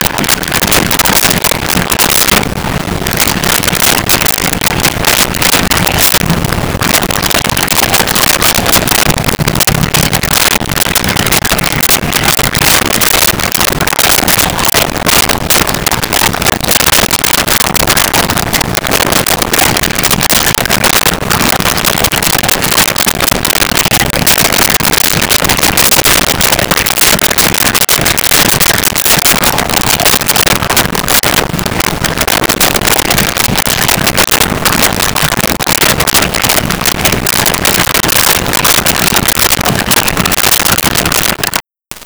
Dog Eating Crunching
Dog Eating Crunching.wav